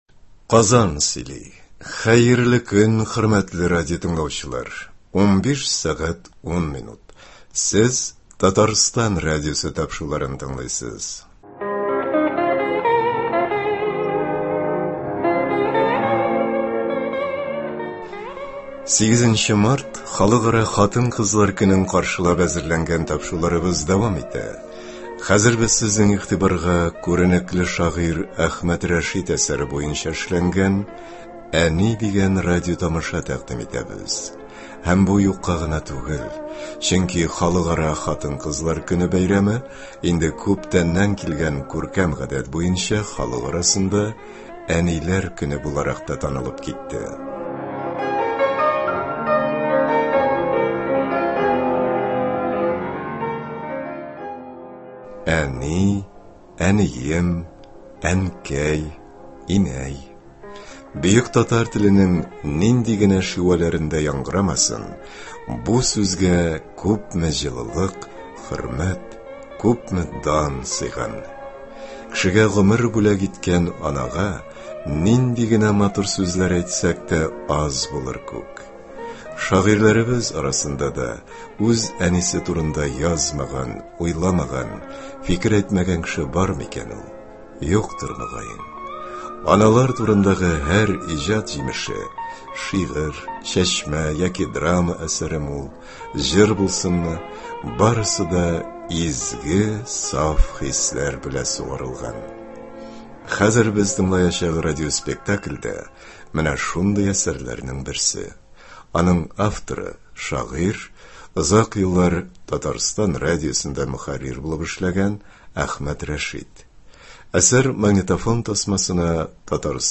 Без сезнең игътибарга күренекле шагыйрь Әхмәт Рәшит әсәре буенча эшләнгән “Әни” дигән радиотамаша тәкъдим итәбез.
Хәзер без тыңлаячак радиопоспектакль дә менә шундый әсәрләрнең берсе.